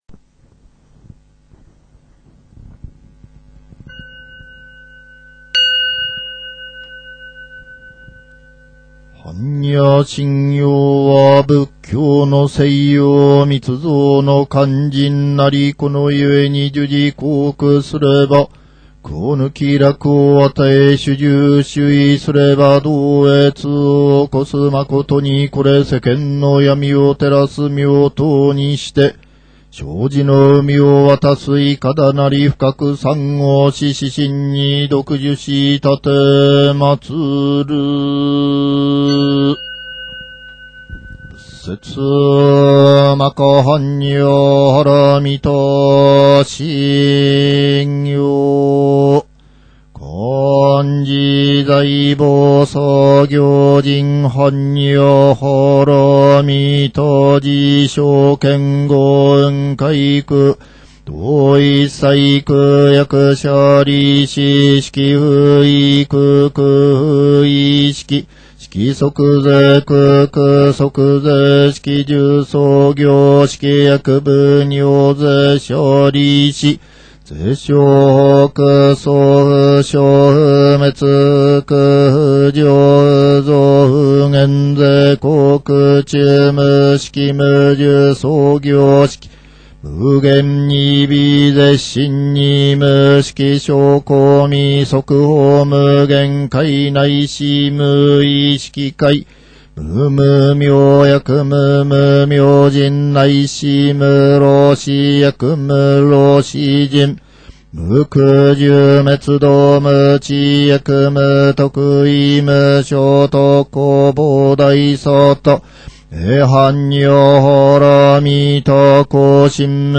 般若心経-ゆっくりバージョン | 法楽寺
お経を聞く（供養したい時･1）
このお経は、いかなる場合も用いられます。供養の際は、ゆっくりとお唱えしましょう。
hannya-slowly.mp3